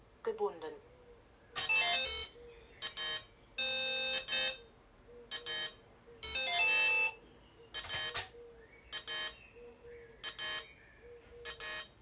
Alarm Beeps warum
Ich meine das das ein Morse Code Alarm ist (kurz - lang) was eigentlich der Alarm für die Höhe ist.
Alarm.wav